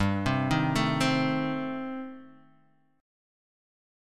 Eb6b5/G chord